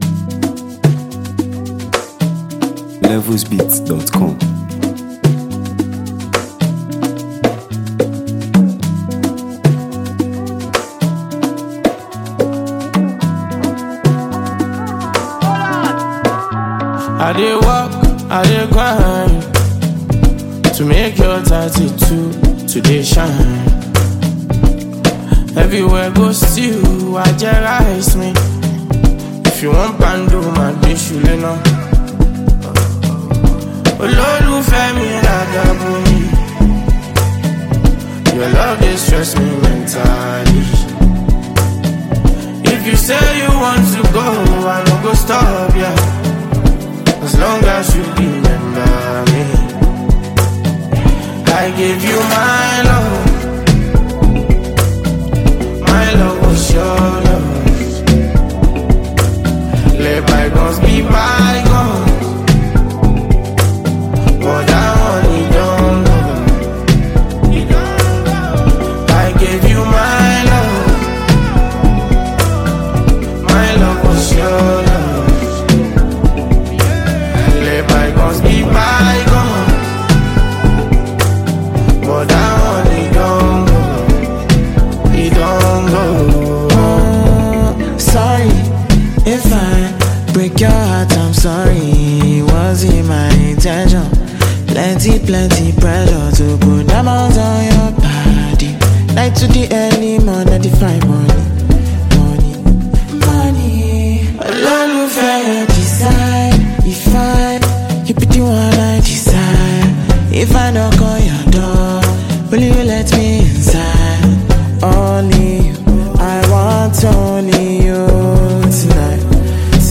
Well-known Nigerian street-pop sensation and songwriter
blends heartfelt lyrics with soulful rhythms
raw delivery
melodic finesse